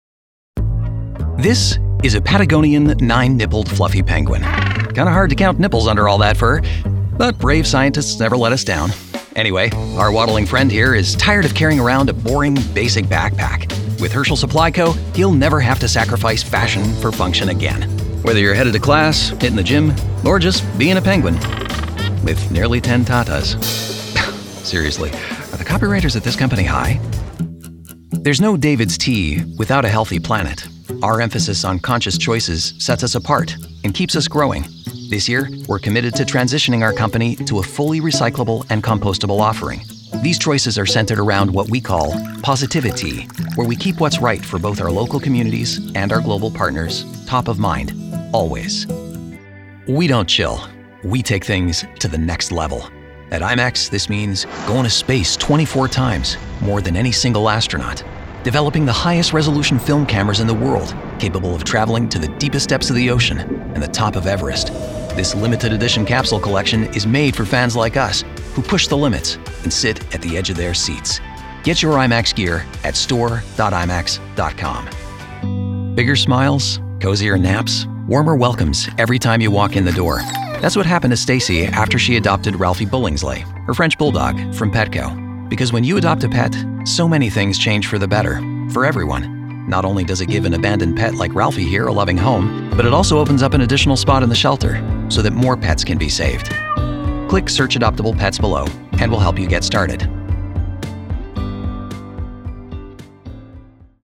Male
American English (Native) , Canadian English (Native) , French Canadian
Approachable, Assured, Authoritative, Confident, Conversational, Corporate, Deep, Energetic, Engaging, Friendly, Funny, Gravitas, Natural, Posh, Reassuring, Sarcastic, Smooth, Soft, Upbeat, Versatile, Warm, Witty
Microphone: Sennheiser 416